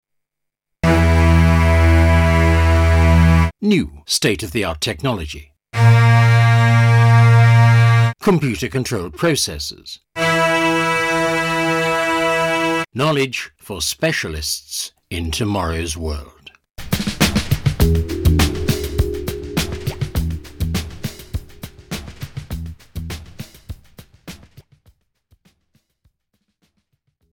britisch
Sprechprobe: Werbung (Muttersprache):